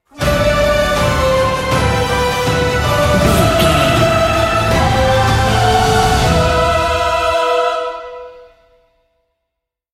Uplifting